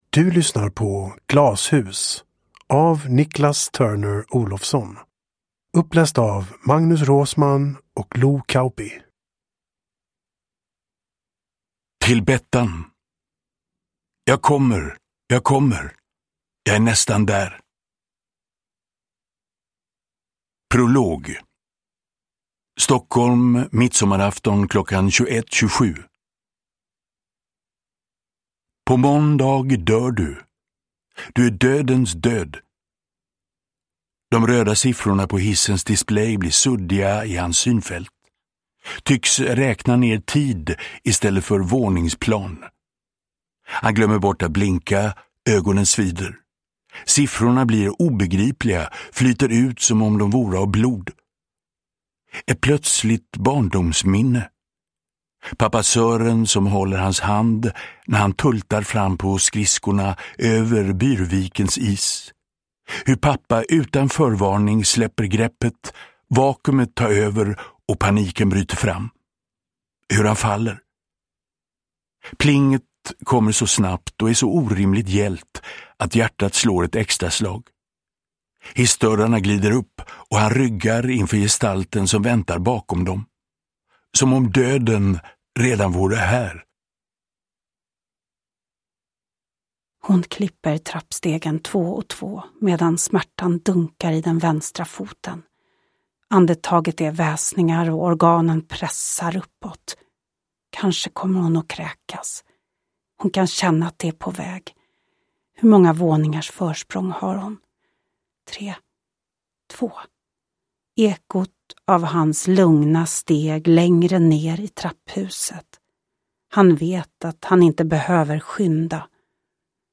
Glashus – Ljudbok
Uppläsare: Magnus Roosmann